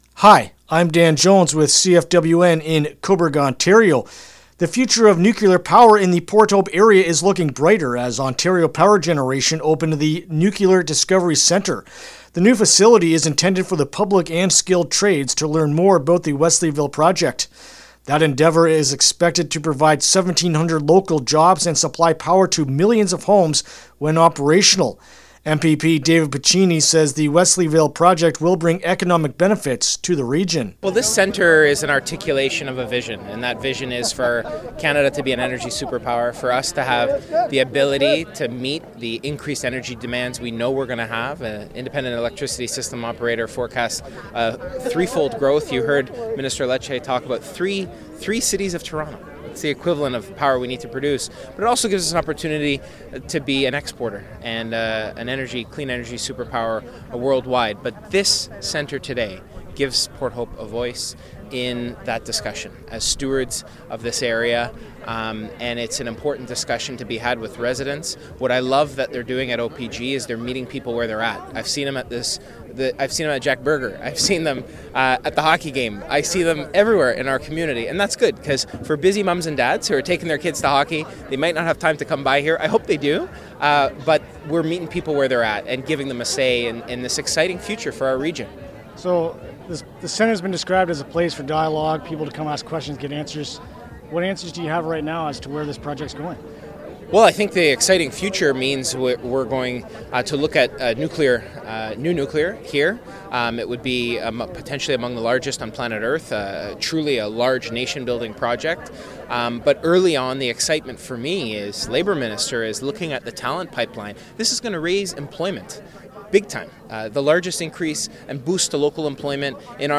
Nuclear-Discovery-Centre-Interview-LJI.mp3